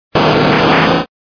Fichier:Cri 0009 DP.ogg